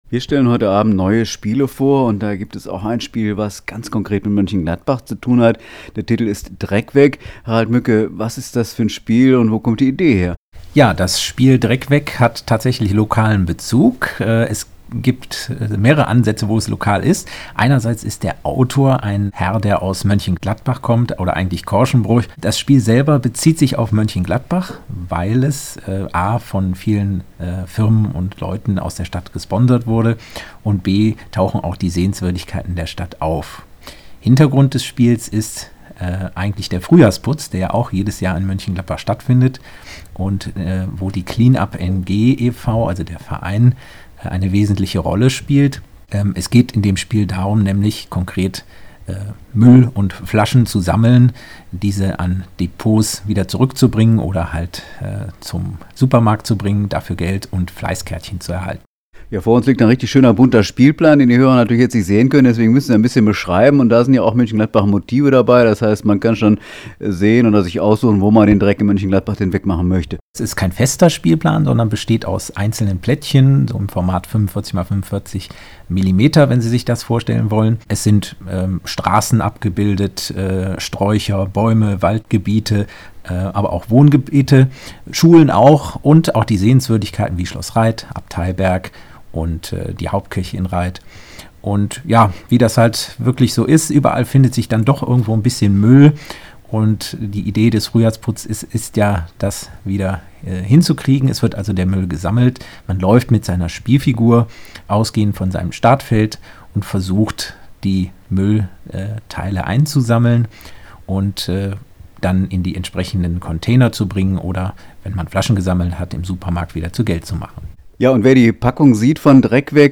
Niersradio Interview2.mp3